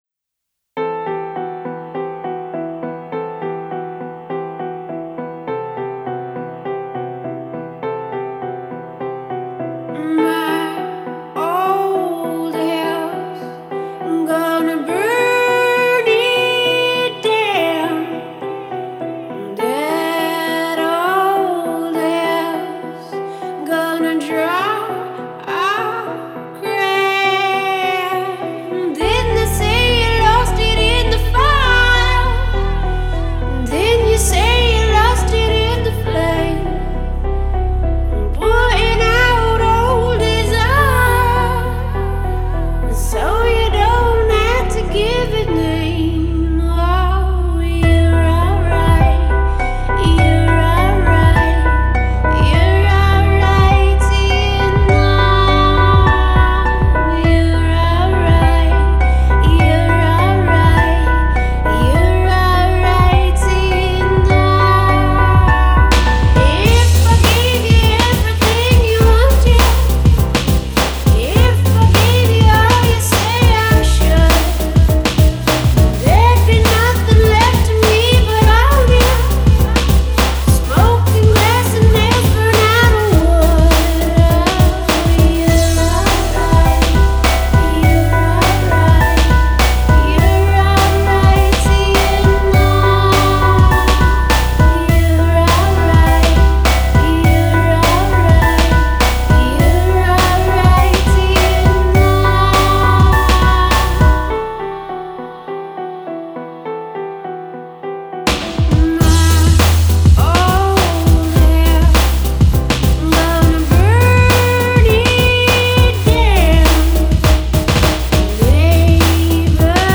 indie rock, chamber music, and hip-hop